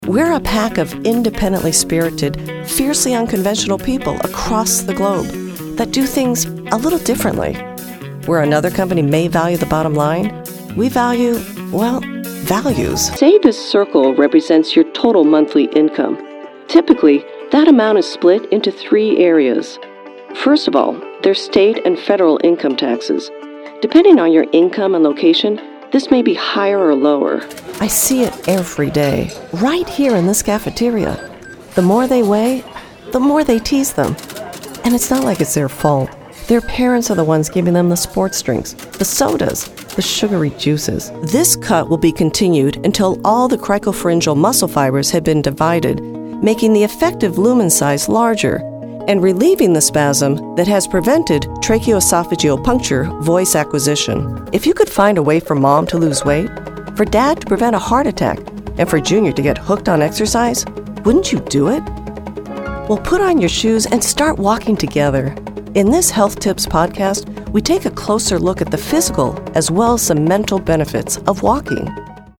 Warm, professional, corporate, articulate, friendly, animated, fun, humorous, wry... and much more
Sprechprobe: Industrie (Muttersprache):
My home studio is fully equipped to provide you with clean, edited and finished audio files.